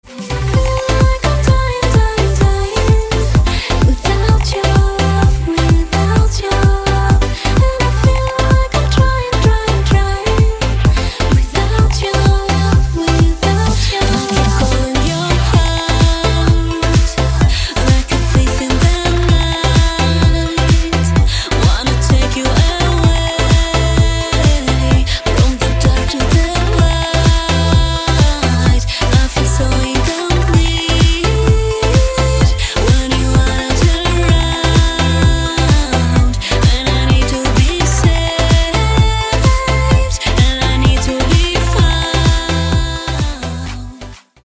• Качество: 192, Stereo
Танцевальный рингтон на ремикс песни